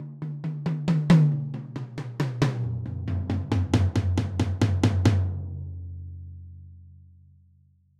Southside Percussion (6).wav